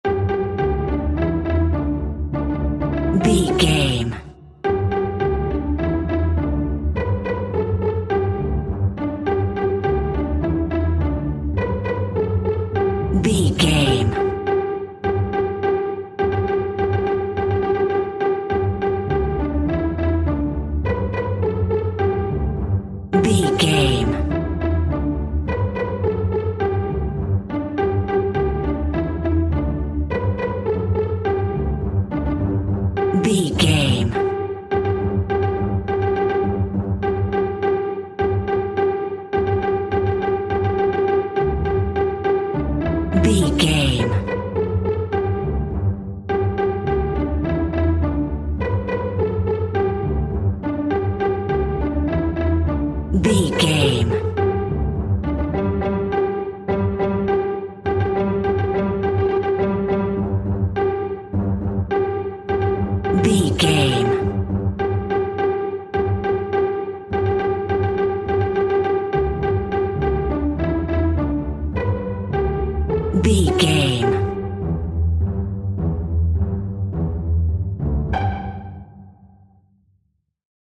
Ionian/Major
kids music